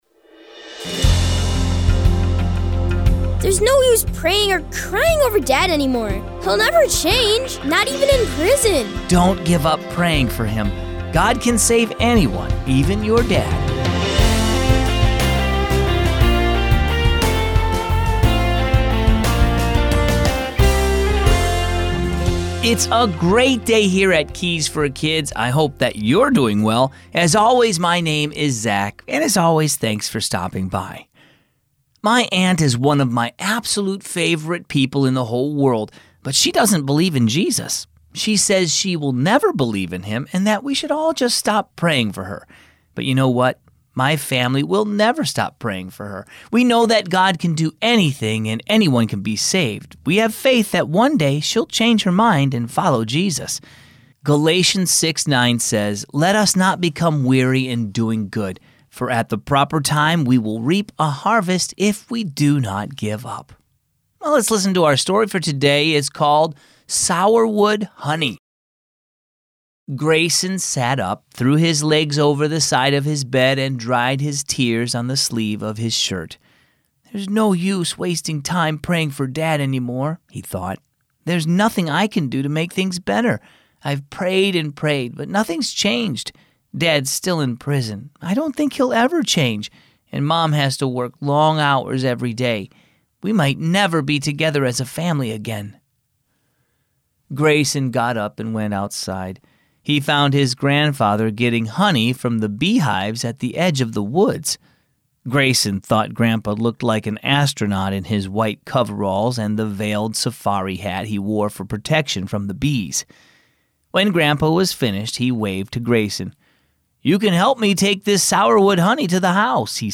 Bible Reading: 1 Timothy 1:12–17